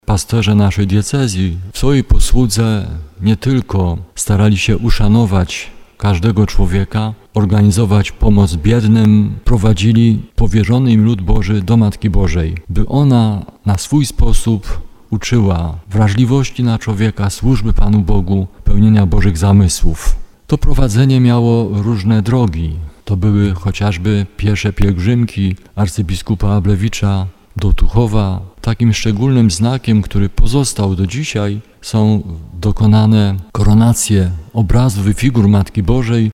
W bazylice katedralnej w Tarnowie została odprawiona Msza Święta pod przewodnictwem biskupa Andrzeja Jeża w intencji zmarłych biskupów diecezji tarnowskiej. W homilii bp Stanisław Salaterski podkreślił głębokie przywiązanie biskupów do Matki Bożej i troskę o formowanie wiernych w duchu maryjnym.